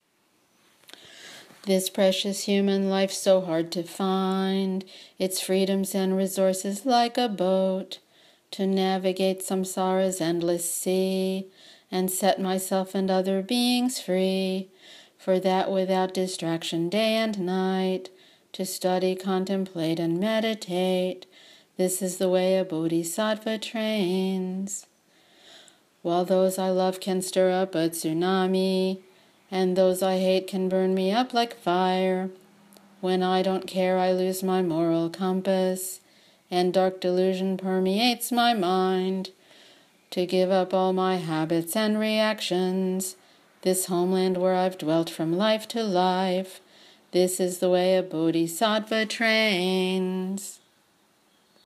Verses 1 and 2 chanted 1x.